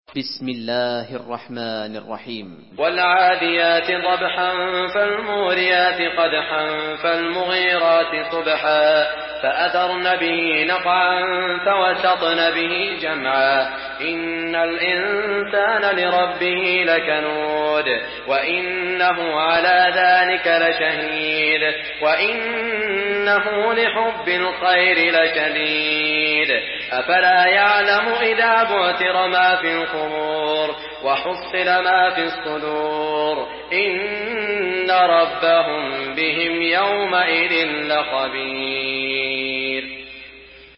Surah Al-Adiyat MP3 in the Voice of Saud Al Shuraim in Hafs Narration
Murattal Hafs An Asim